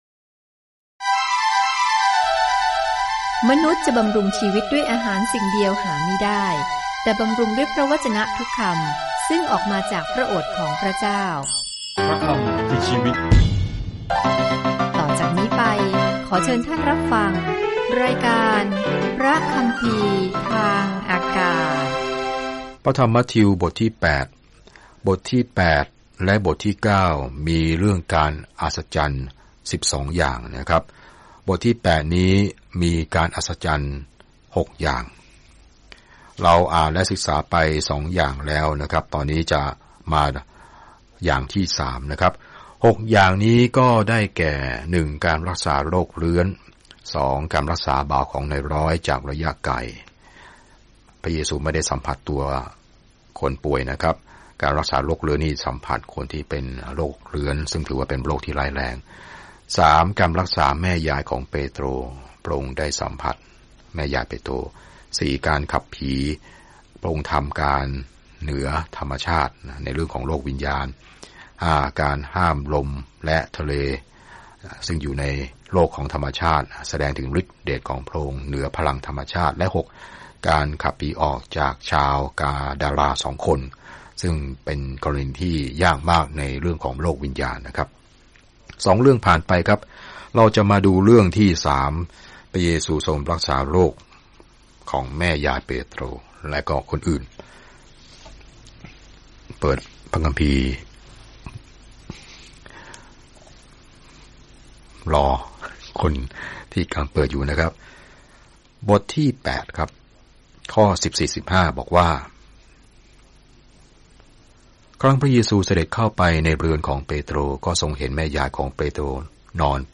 เดินทางทุกวันผ่านมัทธิวในขณะที่คุณฟังการศึกษาด้วยเสียงและอ่านข้อที่เลือกจากพระวจนะของพระเจ้า